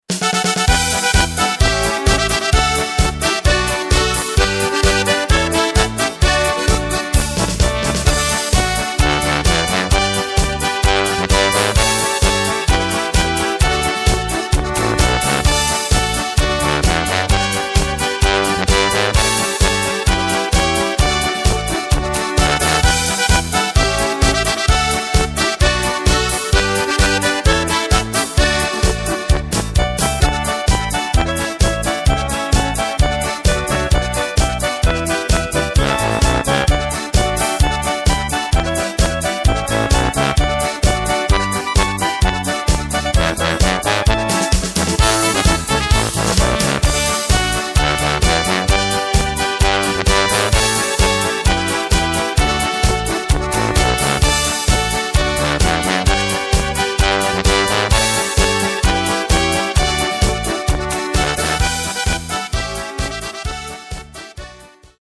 Takt: 4/4 Tempo: 130.00 Tonart: D